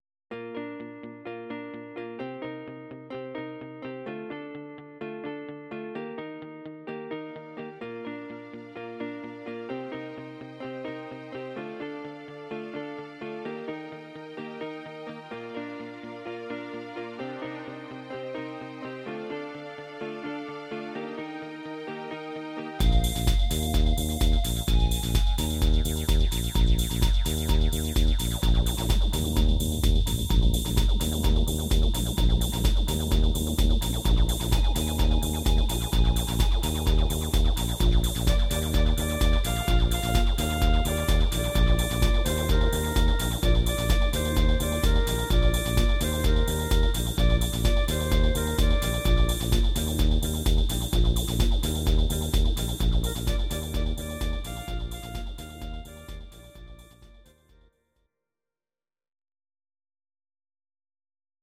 Please note: no vocals and no karaoke included.